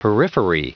Prononciation du mot periphery en anglais (fichier audio)